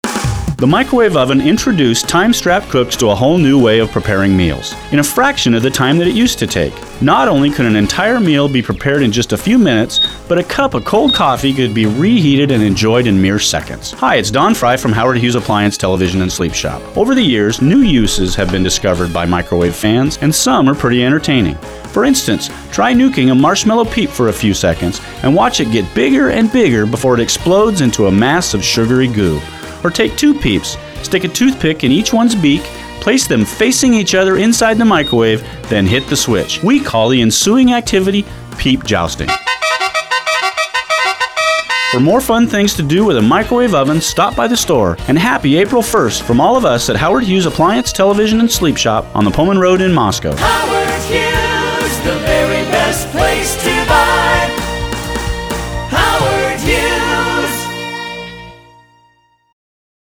This is one of eight different commercials running heavily all day April 1st on all six commercial radio stations in the market.
Though the advertiser’s delivery and jingle sound the same as always, the copy is…a little different.